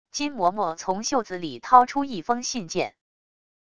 金嬷嬷从袖子里掏出一封信件wav音频生成系统WAV Audio Player